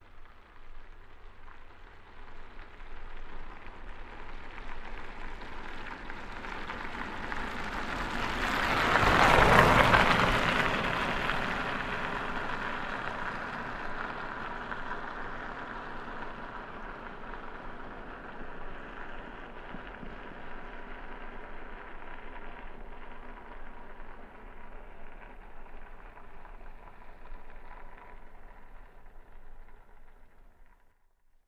Car Tires Snow Studded; Auto By Medium Speed 2x